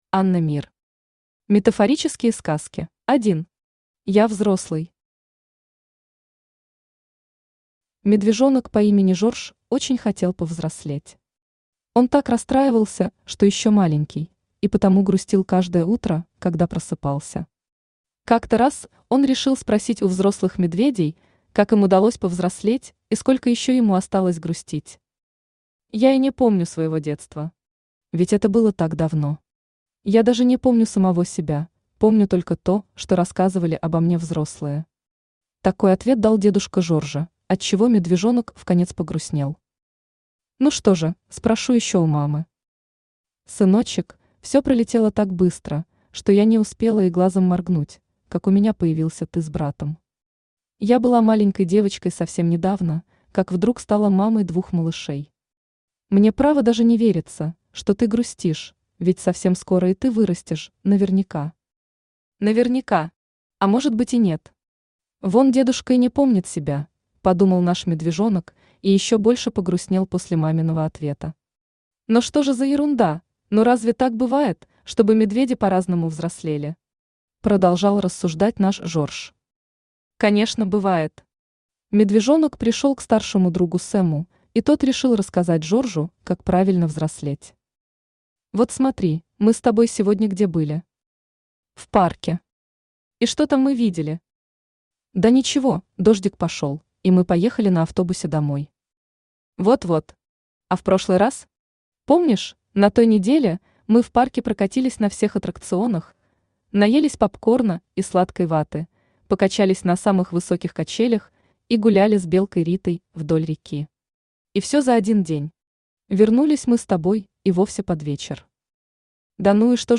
Аудиокнига Метафорические сказки | Библиотека аудиокниг